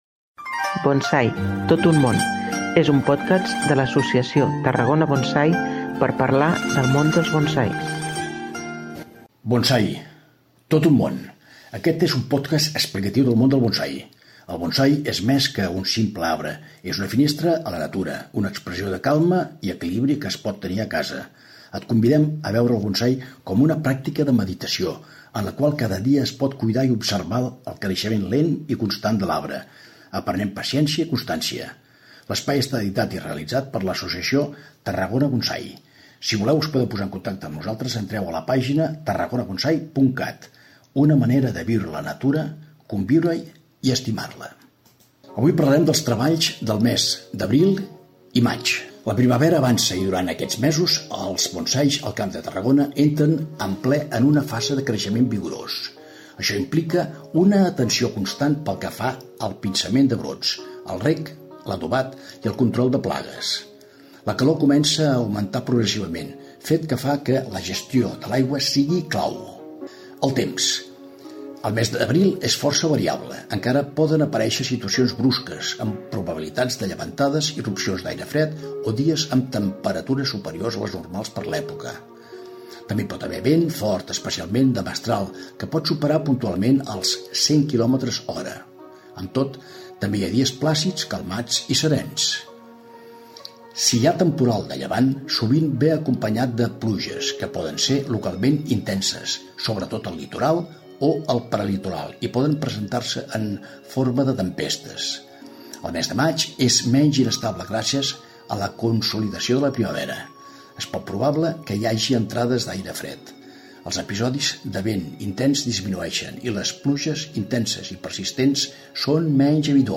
Aquest és un podcast explicatiu del món del bonsai.